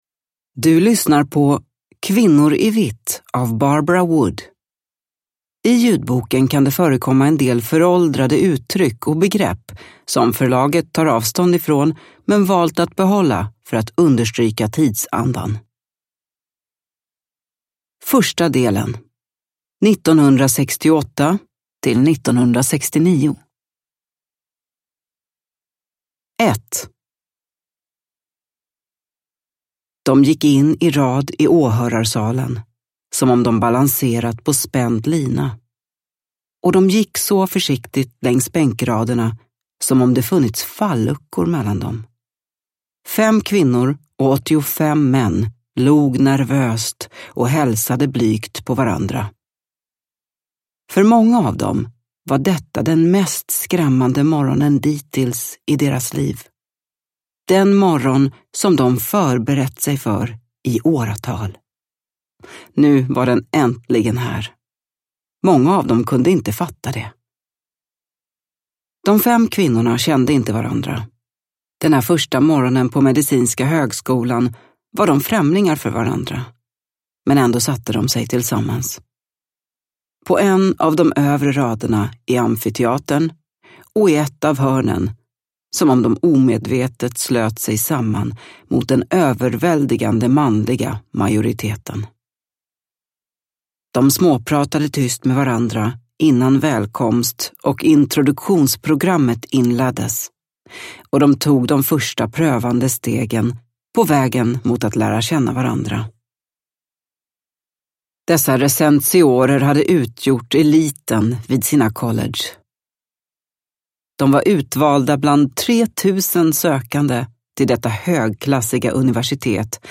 Kvinnor i vitt – Ljudbok – Laddas ner